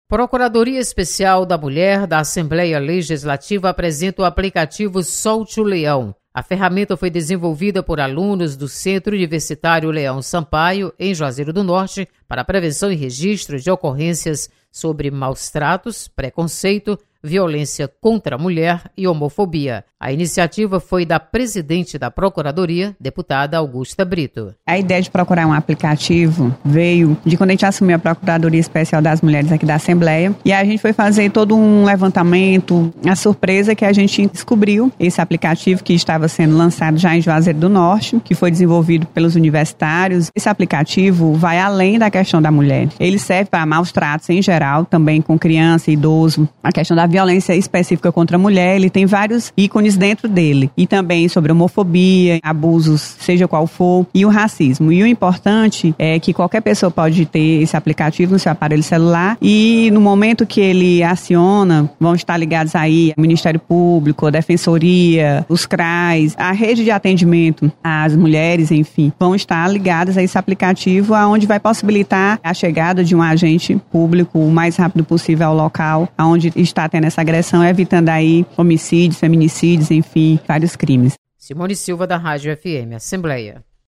Procuradoria Especial da Mulher lança aplicativo para denunciar casos de violência contra as mulheres. Repórter